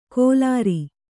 ♪ kōlāri